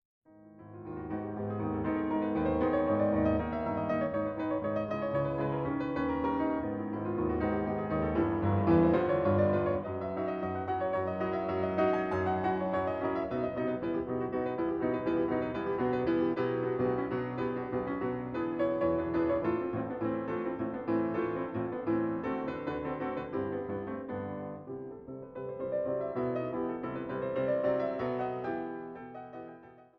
Nr. 11 in H-Dur Vivace